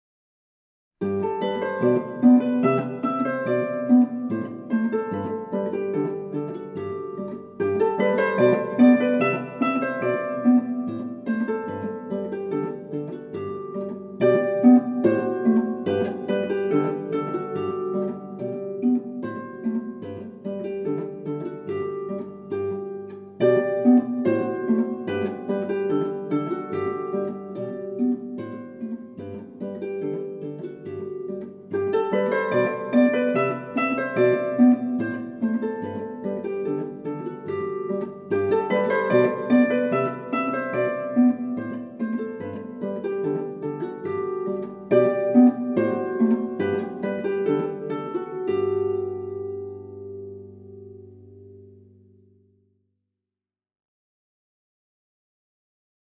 20 short, classic pieces
for solo lever or pedal harp